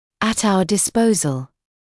[ət ‘auə dɪs’pəuzl][эт ‘ауэ дис’поузл]в нашем распоряжении